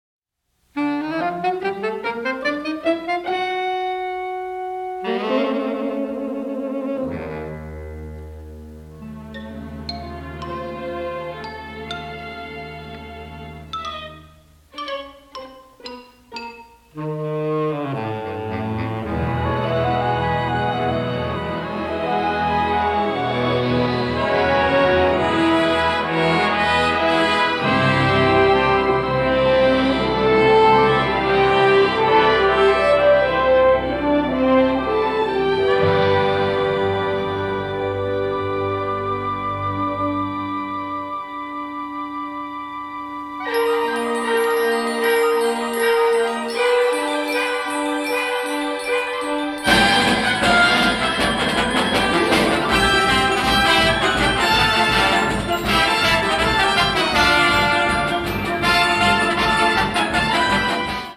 rousingly patriotic score
recognizable war anthems mixed with shades of sarcasm